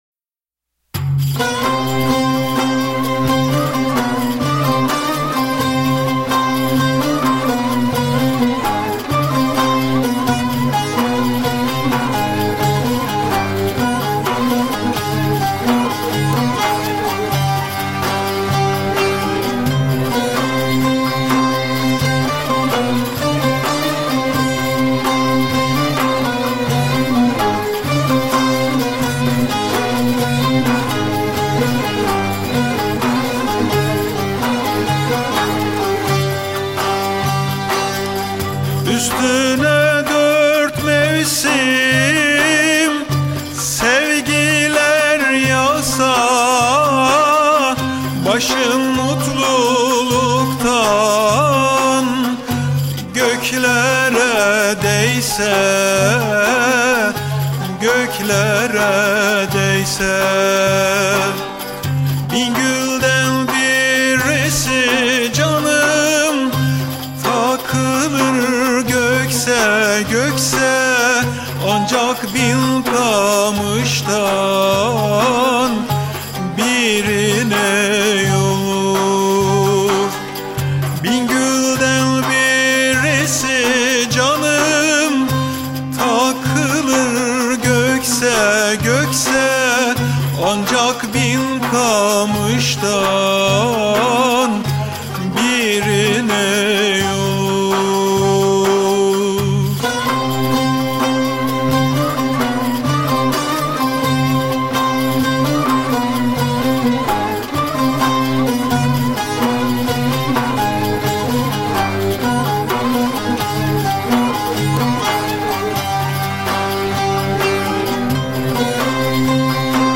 Etiketler: urfa, türkü